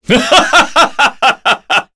Chase-Vox_Happy3_kr.wav